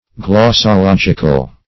Glossological \Glos`so*log"ic*al\, a.
glossological.mp3